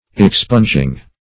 Expunging - definition of Expunging - synonyms, pronunciation, spelling from Free Dictionary